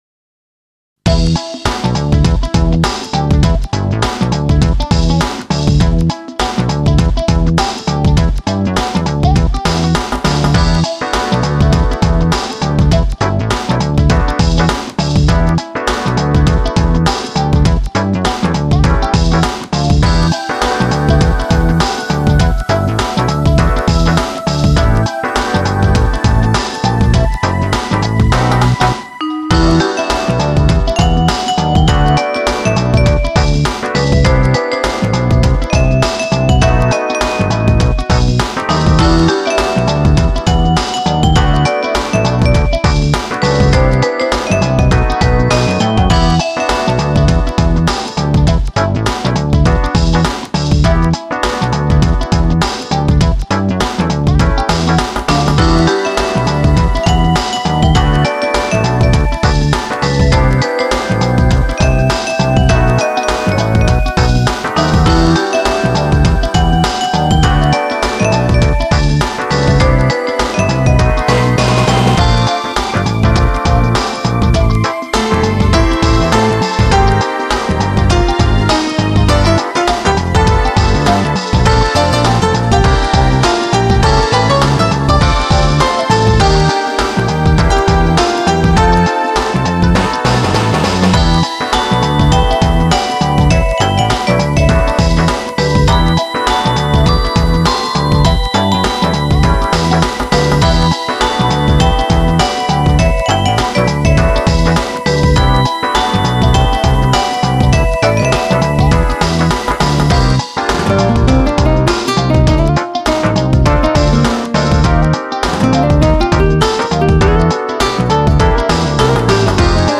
ファイルは「YAMAHA MU1000EX」「Roland SC-8850、INTEGRA-7」で制作したものを